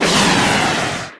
missile.ogg